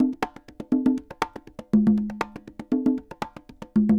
Congas_Salsa 120_5.wav